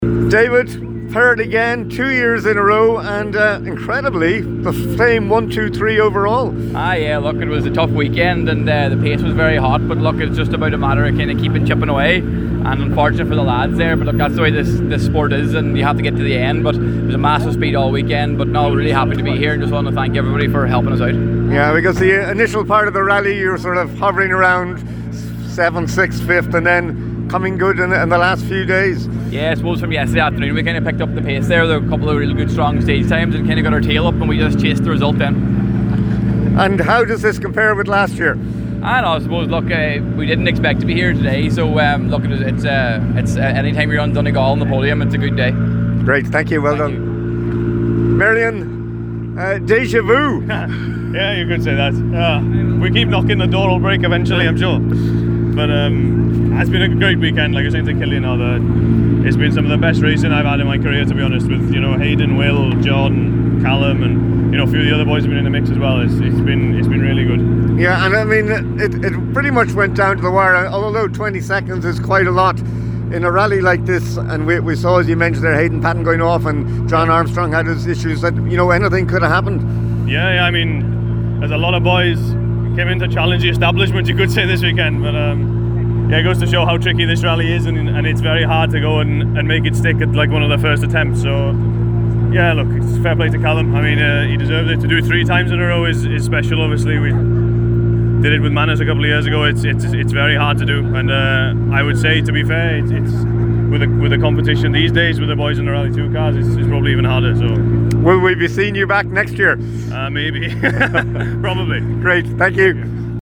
Top competitors react to enthralling Donegal International Rally – Finish-line chats